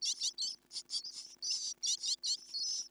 巣立ち直前になると多重(音程が複数の震わした音)で鳴けるようになる。